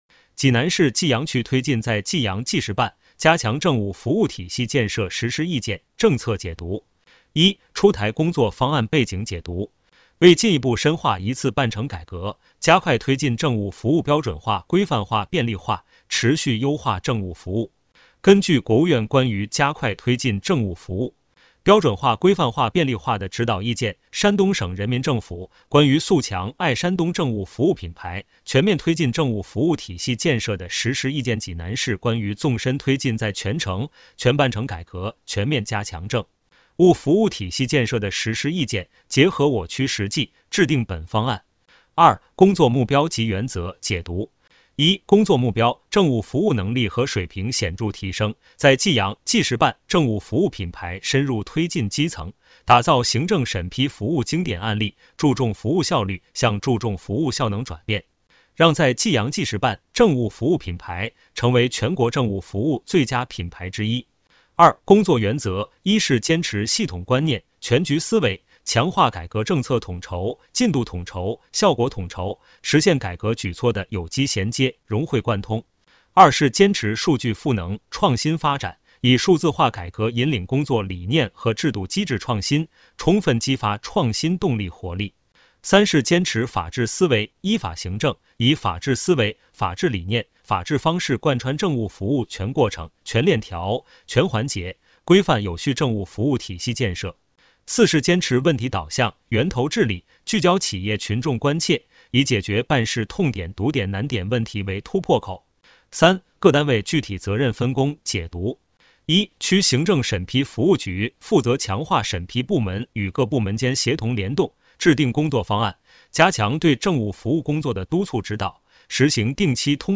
首页 > 政务公开 > 有声朗读 > 内容详情
有声朗读《济南市济阳区推进“在济阳·济时办”加强政务服务体系建设的实施意见》政策解读.mp3